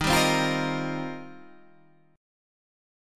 Eb6add9 chord